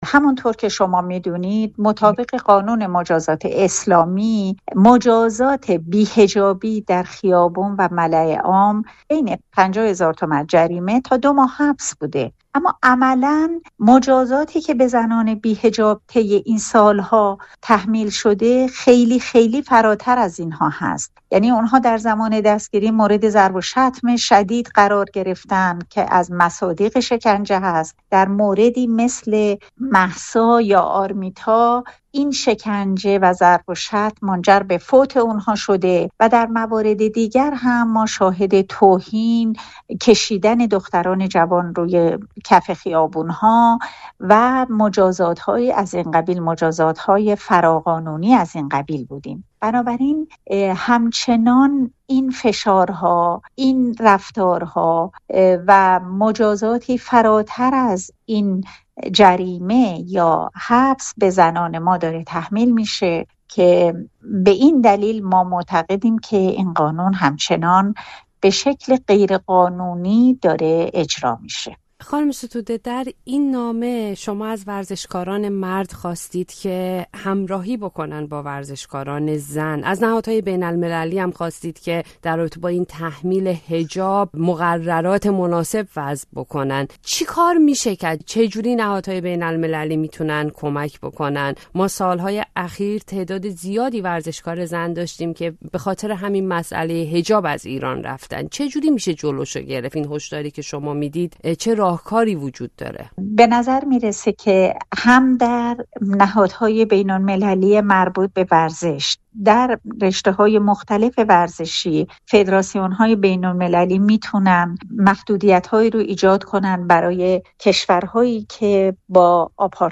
گفتگو کرده